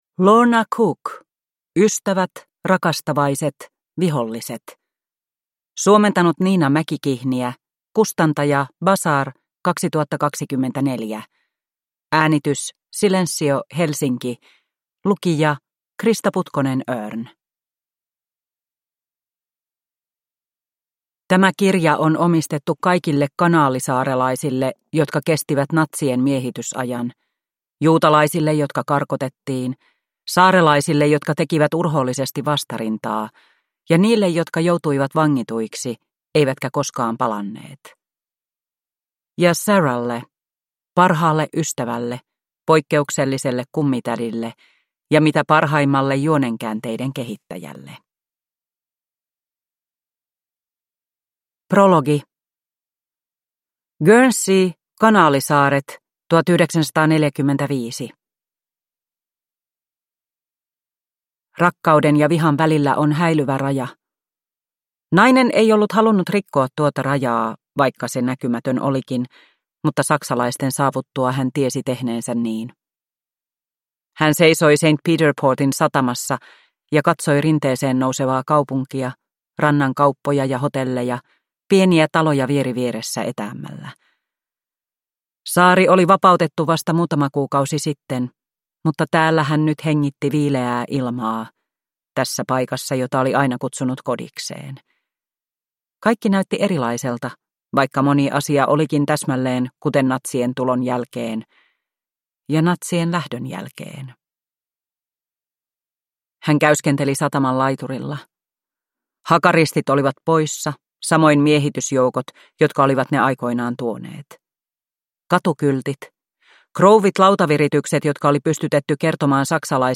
Ystävät, rakastavaiset, viholliset – Ljudbok